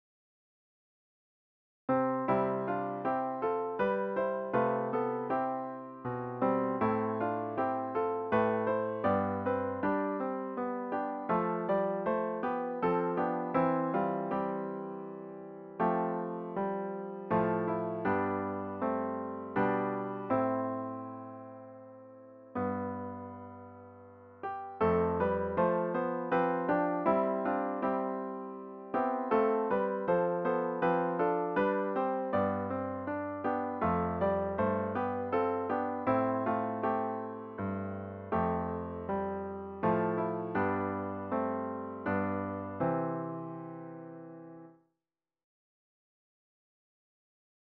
The hymn should be performed at a relaxed♩= ca. 80.